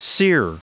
Prononciation du mot sere en anglais (fichier audio)
Prononciation du mot : sere